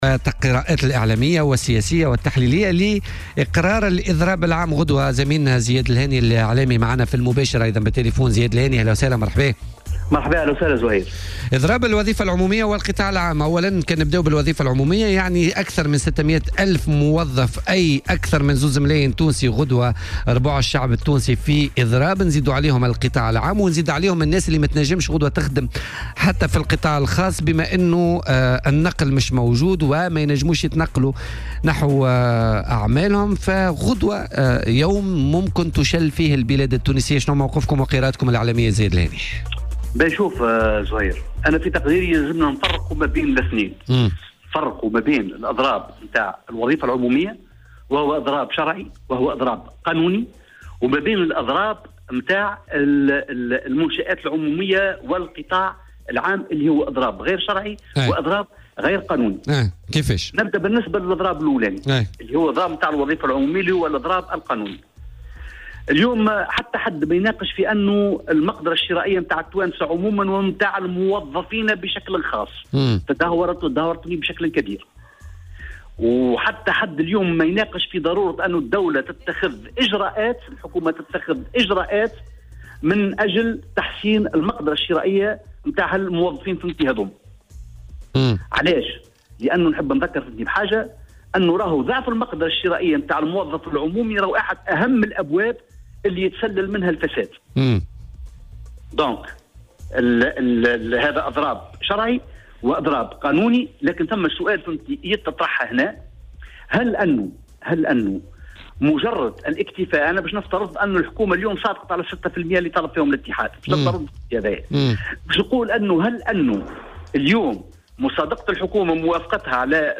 وقال في مداخلة هاتفية مع "بوليتيكا" على "الجوهرة أف ام" إن اضراب الوظيفة العمومية في المقابل يعتبر قانونيا.